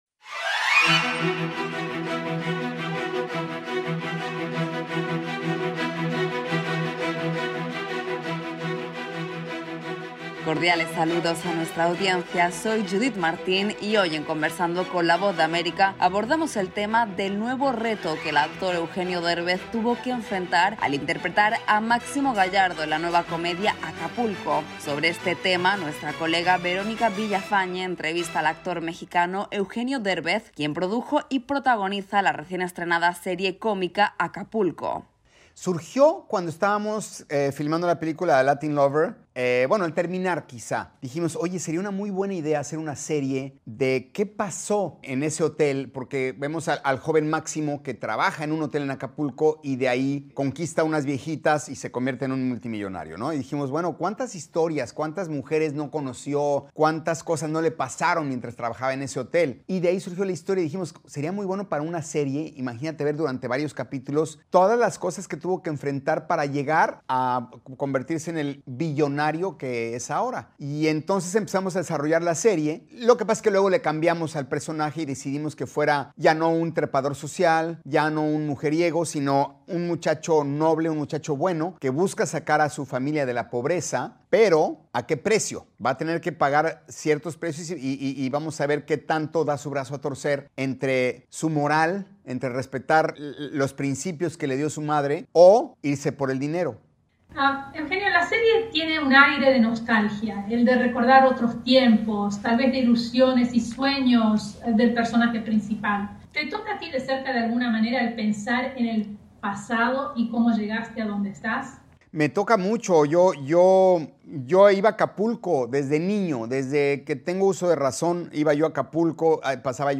Conversamos con el actor Eugenio Derbez, protagonista y productor de la serie “Acapulco” y sus reflexiones sobre su último proyecto en el que busca romper con los estereotipos habituales sobre América Latina y traer al espectador un retrato más real y positivo de México.